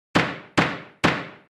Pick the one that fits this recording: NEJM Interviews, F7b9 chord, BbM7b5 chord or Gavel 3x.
Gavel 3x